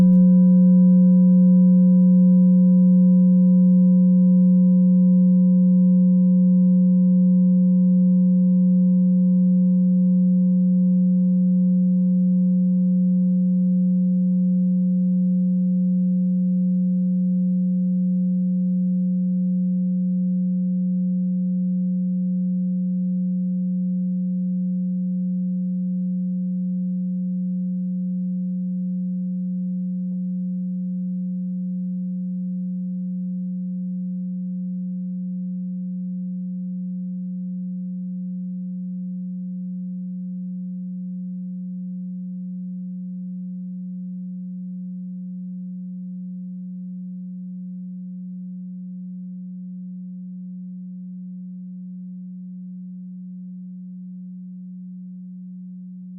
Klangschalen-Typ: Bengalen und Tibet
Klangschale 1 im Set 5
Klangschale Nr.1
(Aufgenommen mit dem Filzklöppel/Gummischlegel)
klangschale-set-5-1.wav